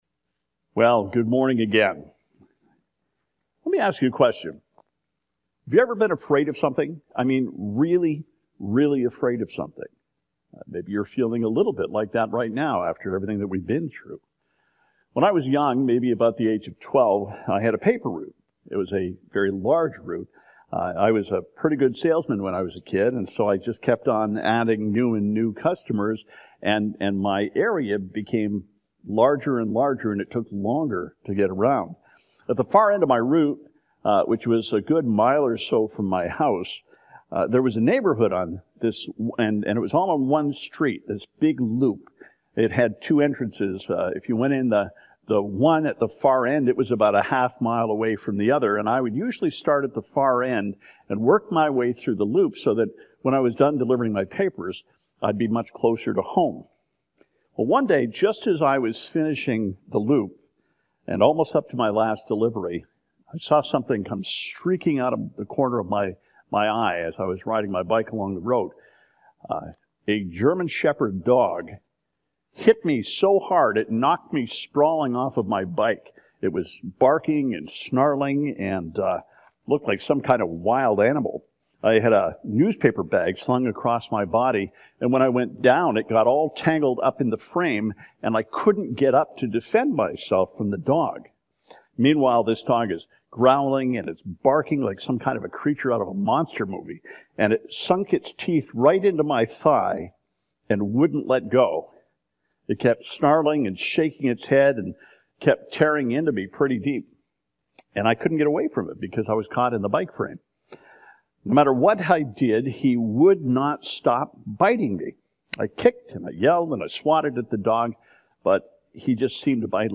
Sermon: “Fear 365” Isaiah 43:1 « FABIC Sermons